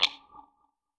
tap.ogg